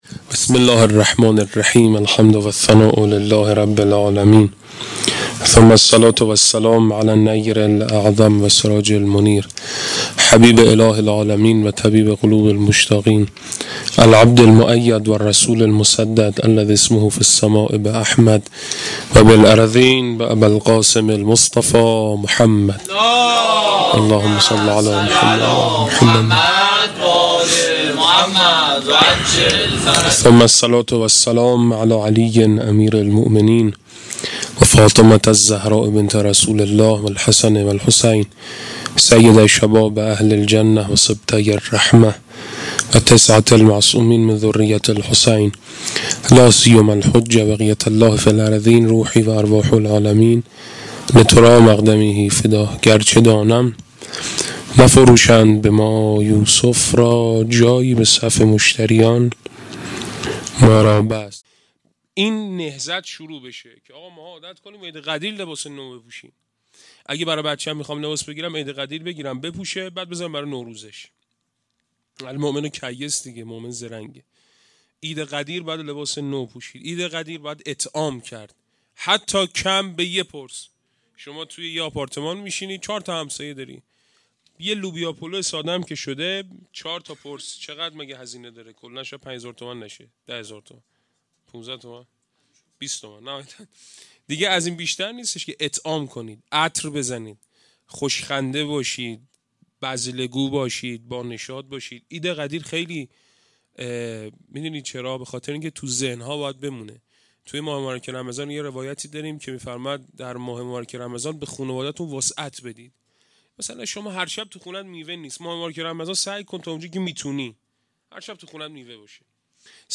Eyde-Ghadir-93-Sokhanrani.mp3